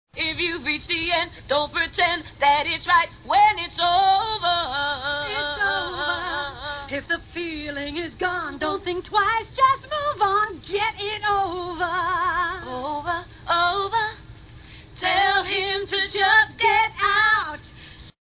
acapella.mp3